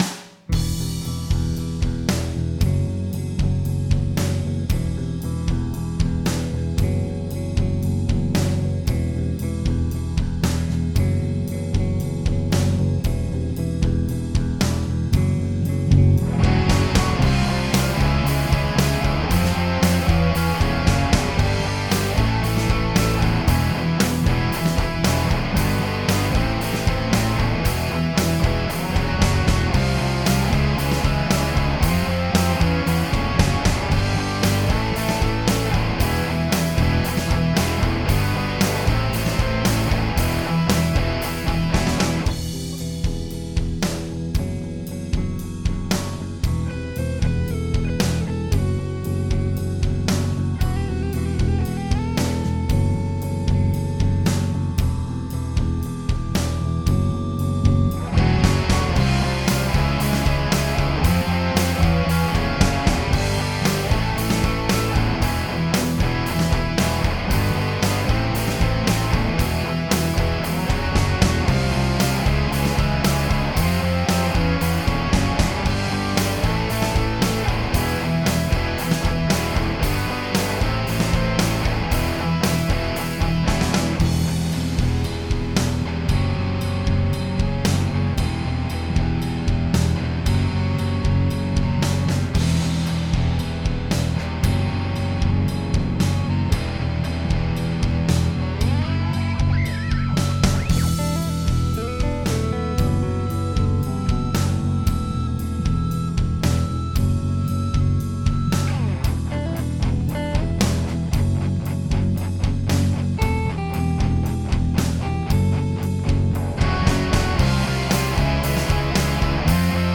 I recorded these tracks with a Shure SM 57 close to the speaker. Playing through a fender mustang modeling amp. Its turned up kind of loud. These pickups are not real hot.